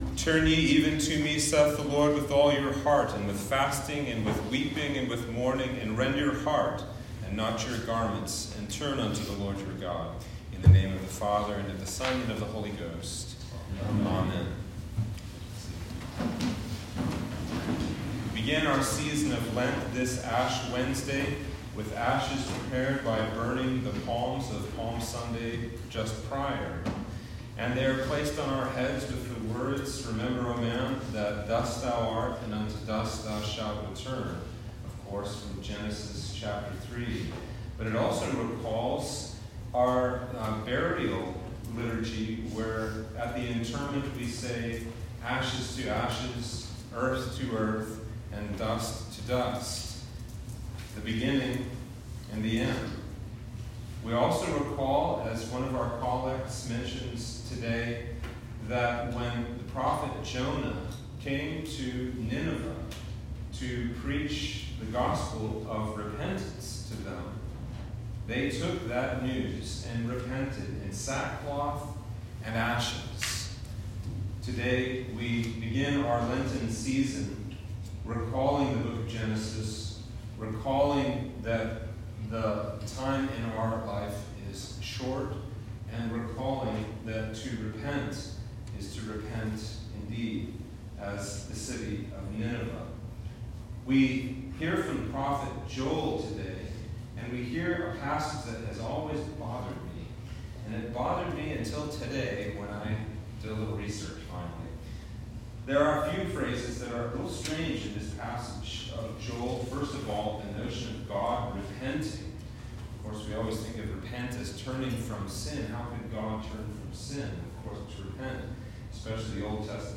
Sermon for Ash Wednesday